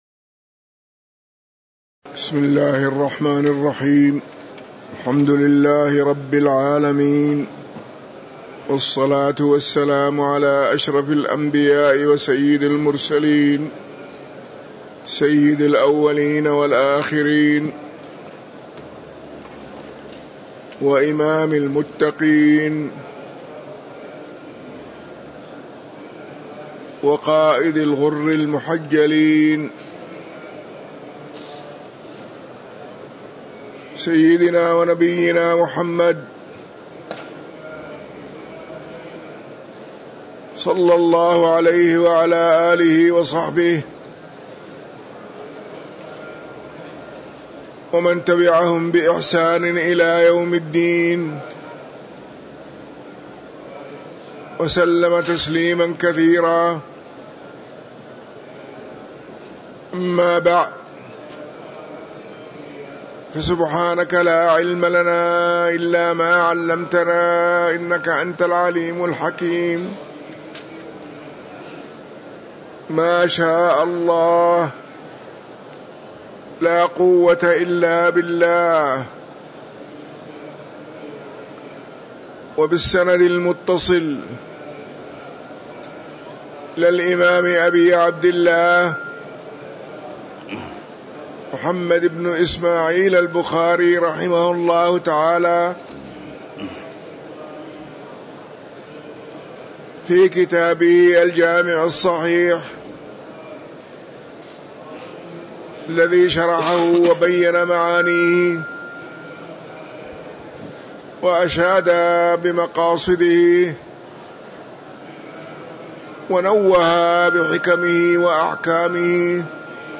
تاريخ النشر ٨ ربيع الأول ١٤٤٤ هـ المكان: المسجد النبوي الشيخ